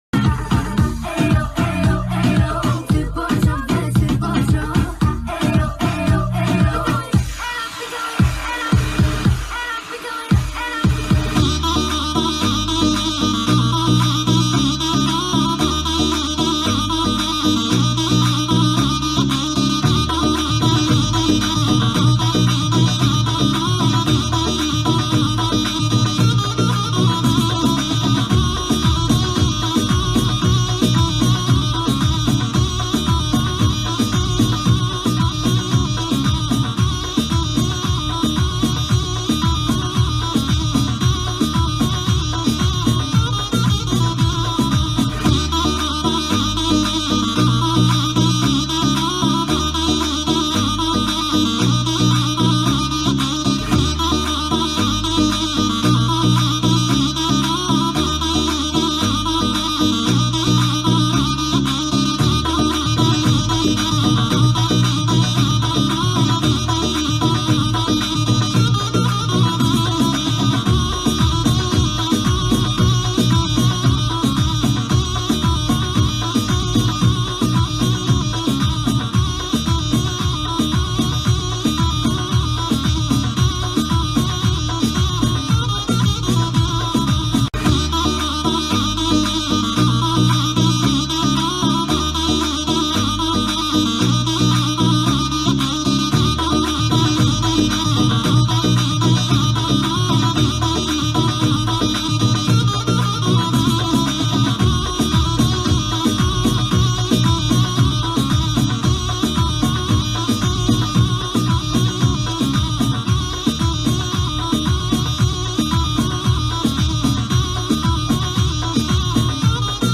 speed up remix
tiktok version sped up